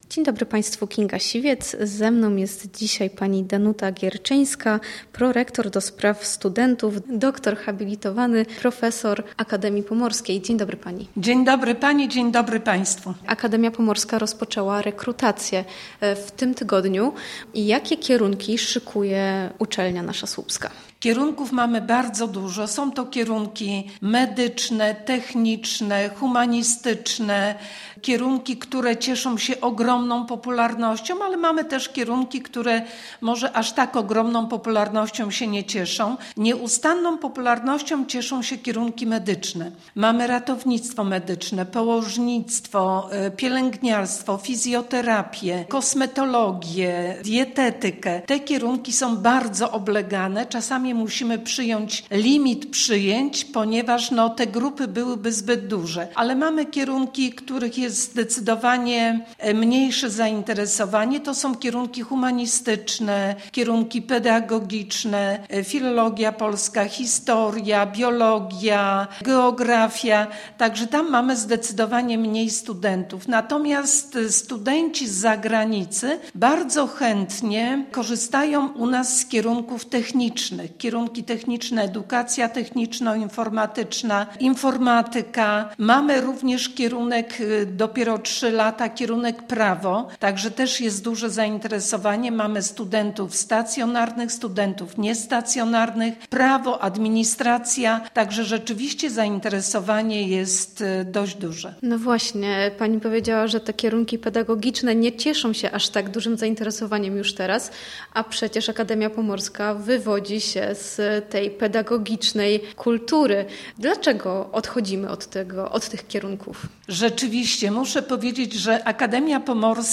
O ofercie AP rozmawialiśmy dziś na naszej antenie